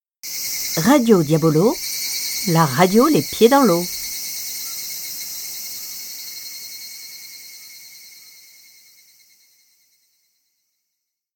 Voix off
13 - 100 ans - Mezzo-soprano